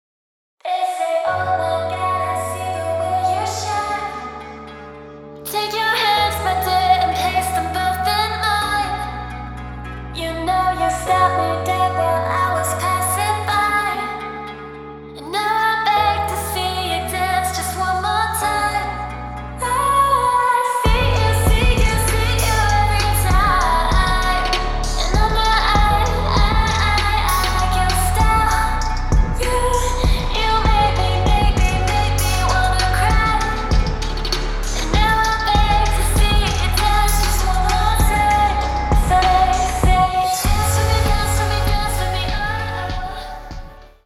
8D-аудио для наушников